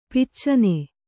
♪ piccane